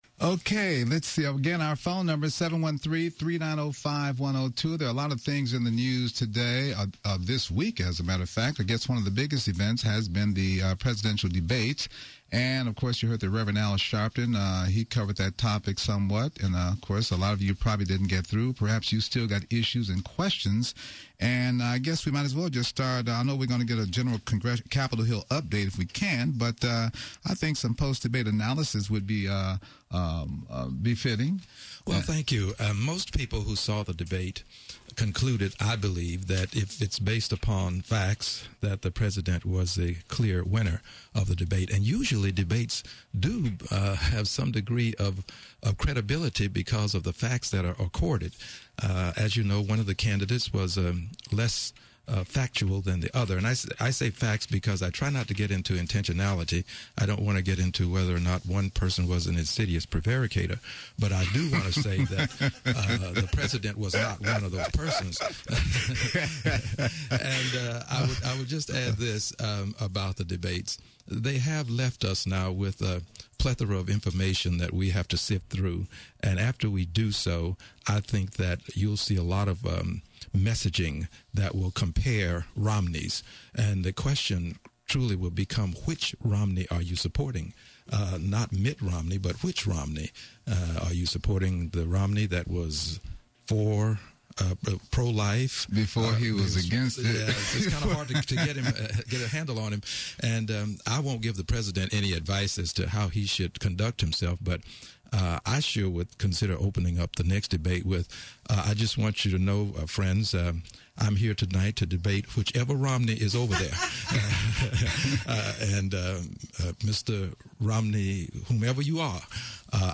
U.S. Congressman Al Green – 9th District of Texas joined us to discuss post-debate Analysis, bring us his Capitol Hill Update, and encourage listeners to get out and VOTE.